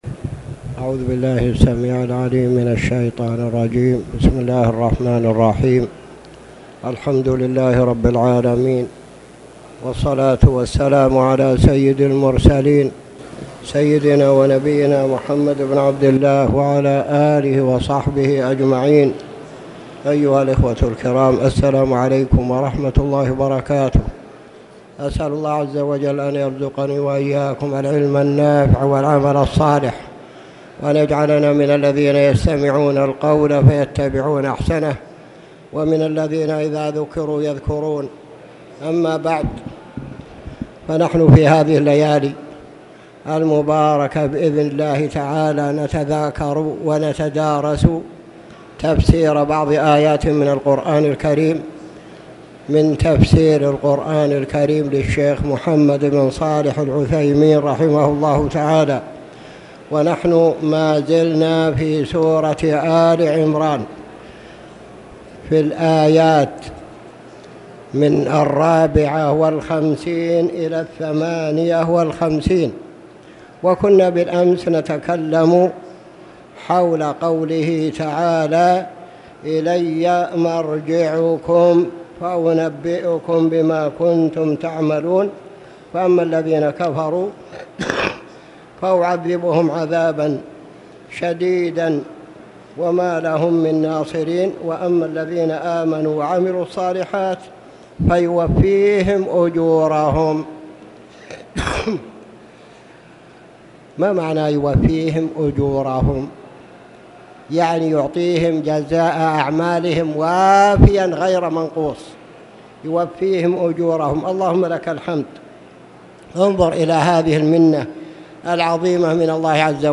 تاريخ النشر ٢ جمادى الآخرة ١٤٣٨ هـ المكان: المسجد الحرام الشيخ